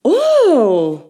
Mujer sorprendida
interjección
mujer
sorpresa
Sonidos: Acciones humanas
Sonidos: Voz humana